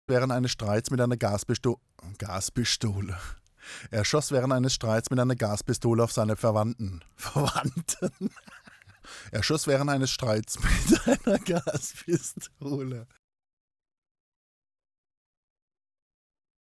Versprecher: Mitschnitte
Mikro wackelt